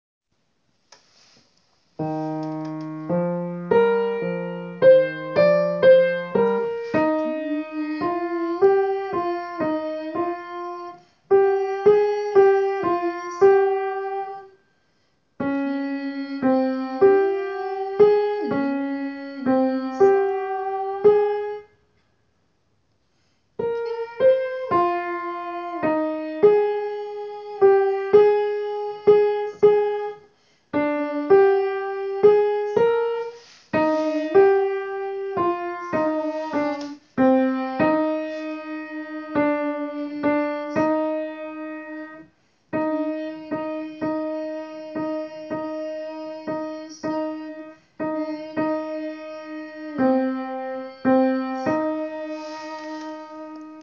Alto
gounod.kyrie_.suite_.alto_.wav